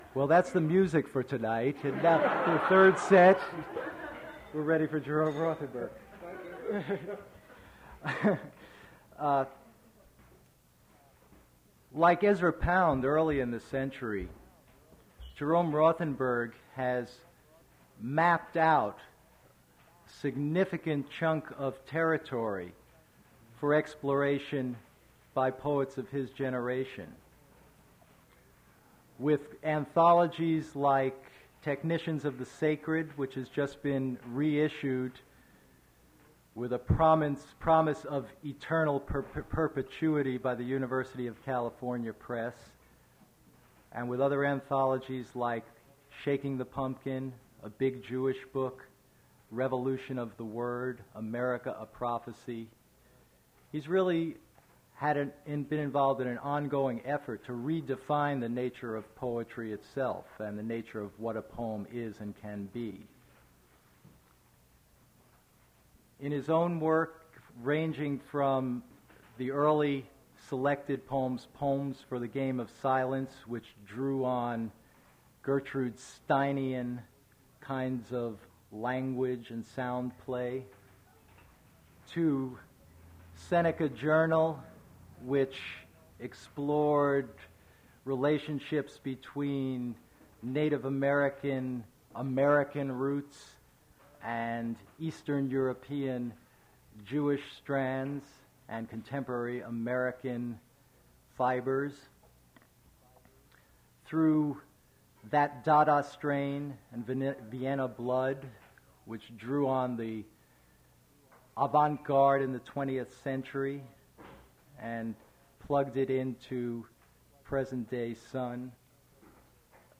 Attributes Attribute Name Values Description Jerome Rothenberg poetry reading at Duff's Restaurant.
mp3 edited access file was created from unedited access file which was sourced from preservation WAV file that was generated from original audio cassette.
Recording is cut in the middle of The Nature Theater of Oklahoma II